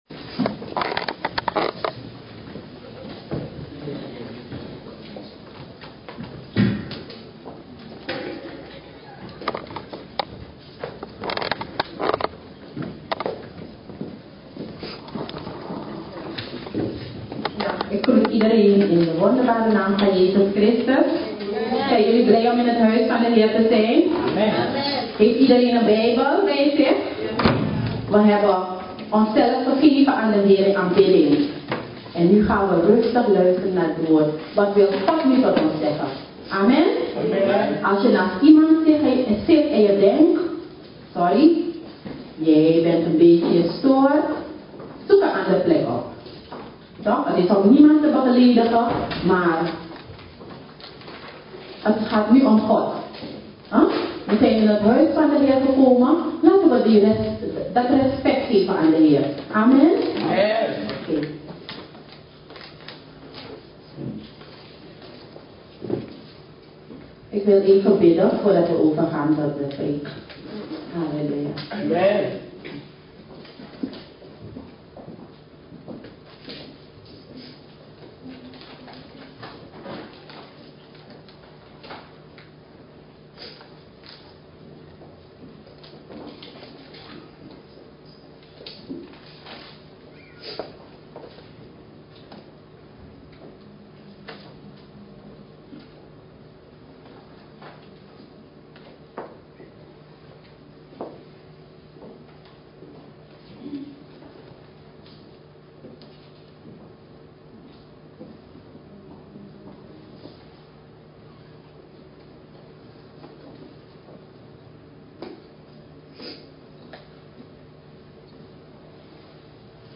Dutch Assembly
sermon